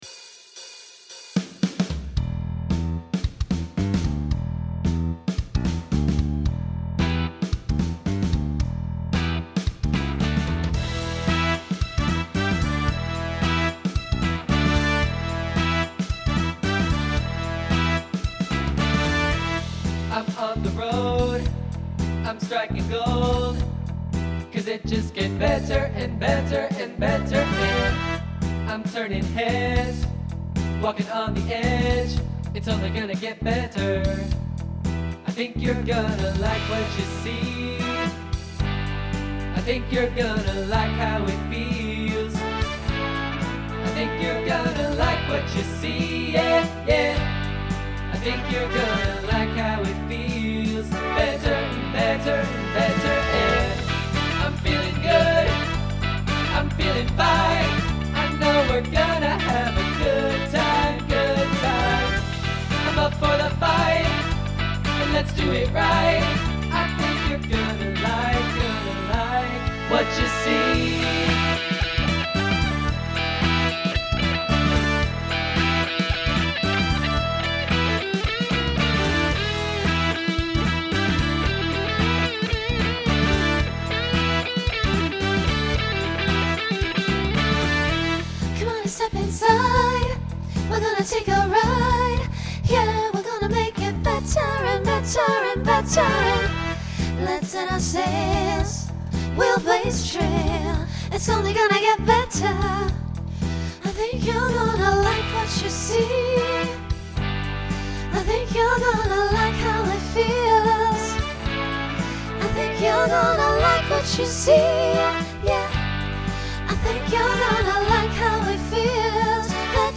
TTB/SSA/SATB
Pop/Dance , Rock
Transition Voicing Mixed